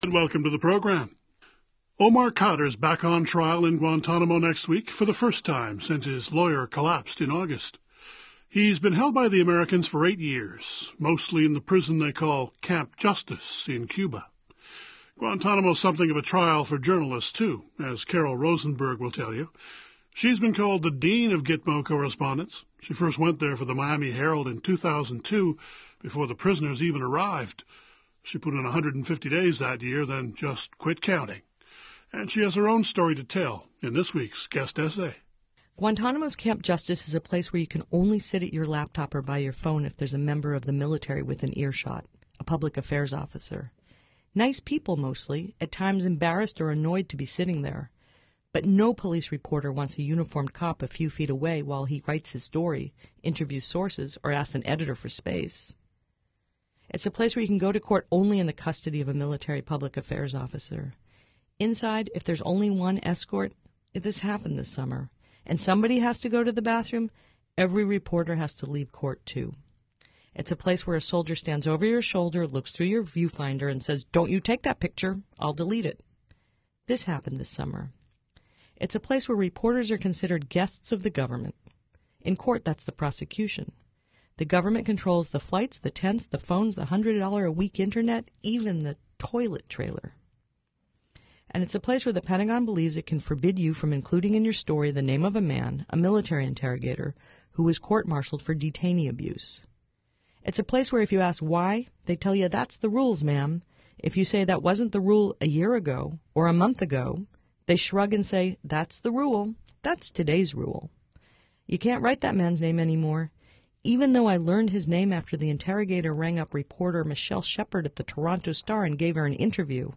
(The full broadcast of Dispatches is available here.)